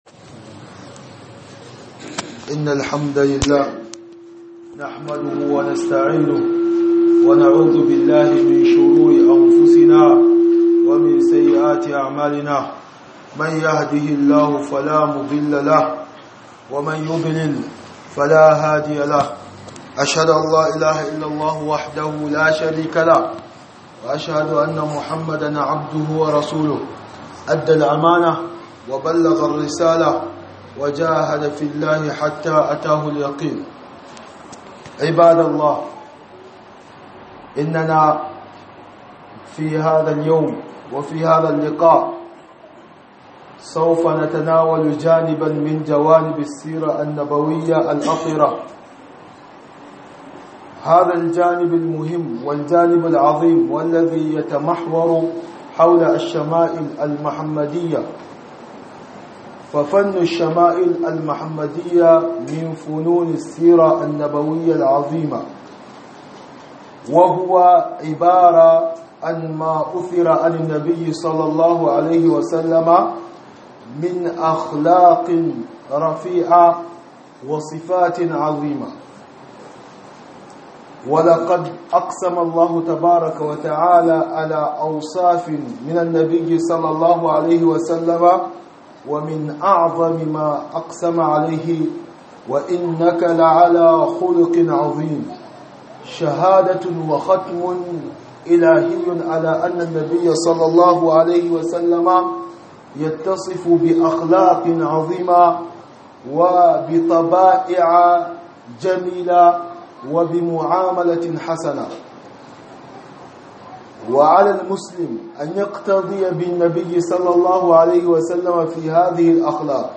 خطبة بعنوان من الشمائل المحمدية